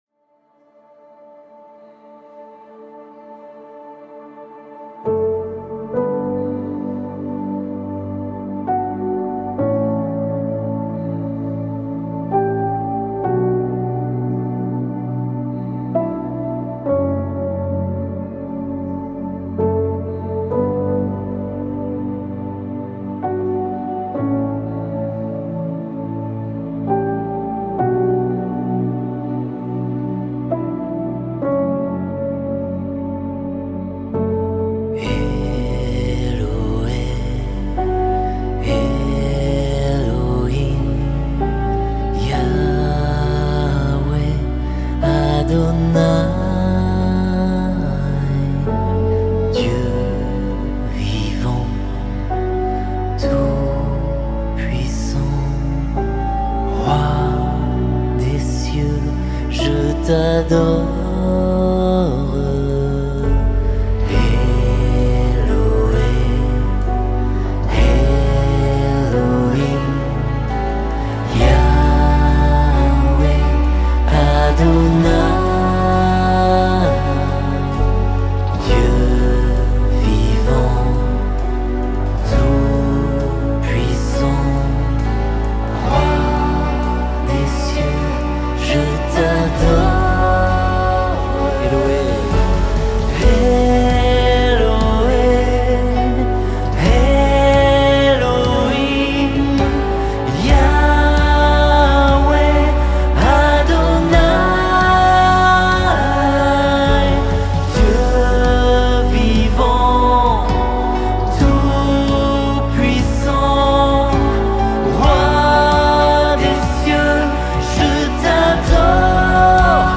166 просмотров 41 прослушиваний 0 скачиваний BPM: 66